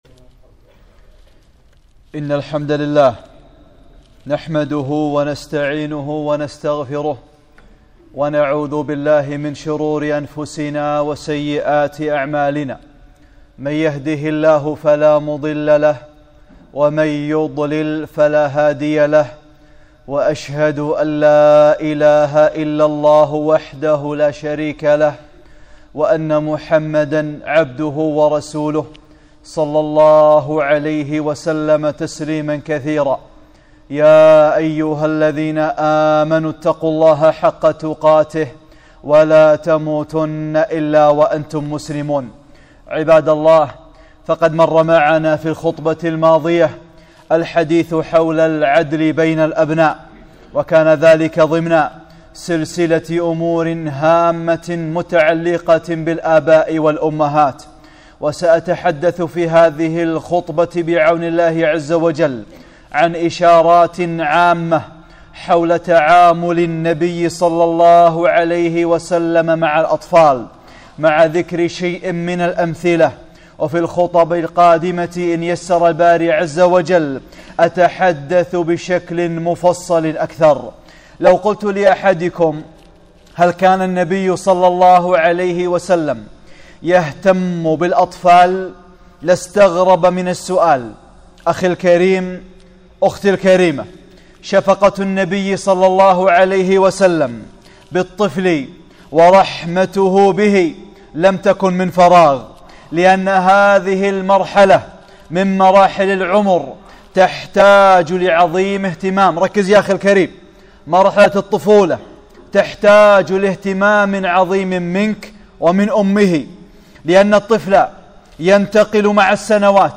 (20) خطبة - الرفق بالأطفال - أمور هامة متعلقة بالآباء والأمهات